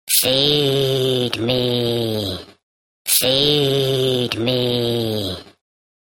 Category Sound Effects